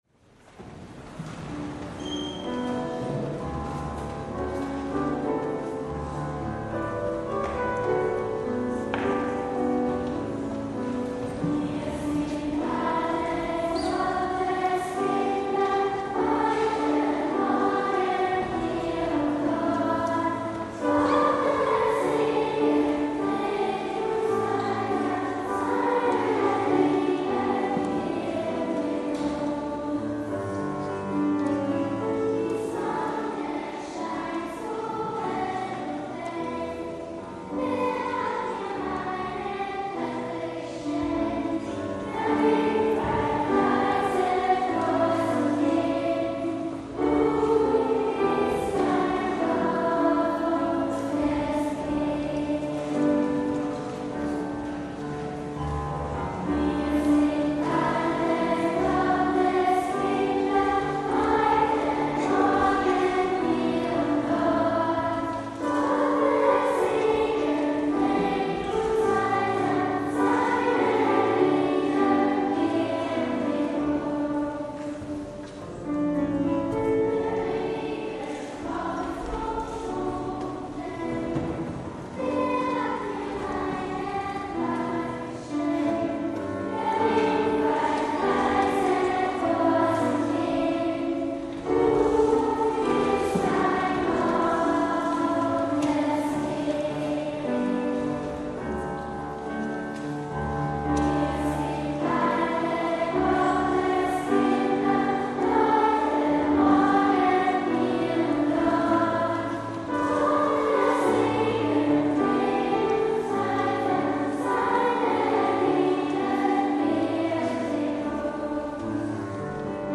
Eucharistiefeier mit Bischof Dr. Alois Schwarz
Kommunion: Wir sind alle Gottes Kinder 4 MB Kinderchor Kommunion: Lord, you have my heart 3 MB Jugendchor Dankgesang: Regina coeli, G. Aichinger 2 MB Gesamtchor Schlussgesang: Selig bist du, Maria, Lp 608 3 MB NGL